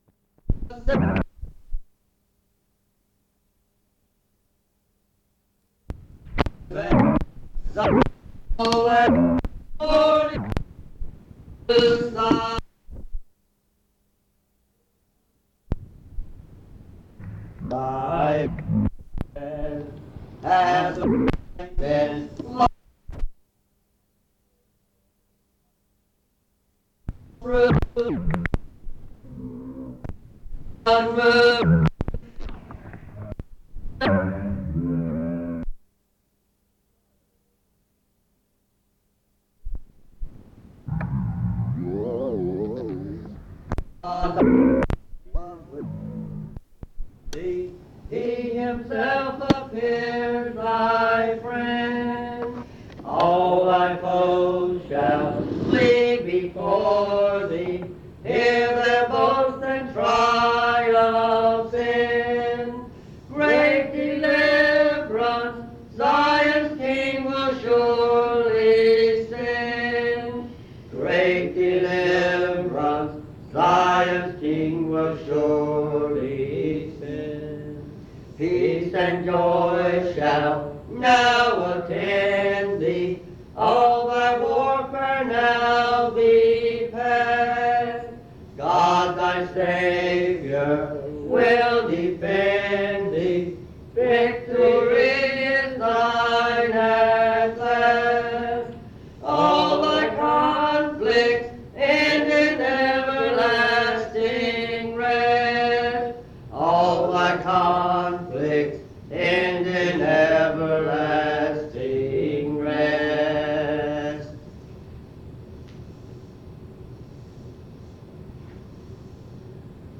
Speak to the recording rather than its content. at Monticello Primitive Baptist Church